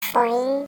bounce1.ogg